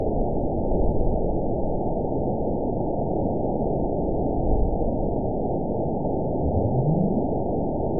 event 920570 date 03/30/24 time 13:43:51 GMT (1 year, 1 month ago) score 8.97 location TSS-AB01 detected by nrw target species NRW annotations +NRW Spectrogram: Frequency (kHz) vs. Time (s) audio not available .wav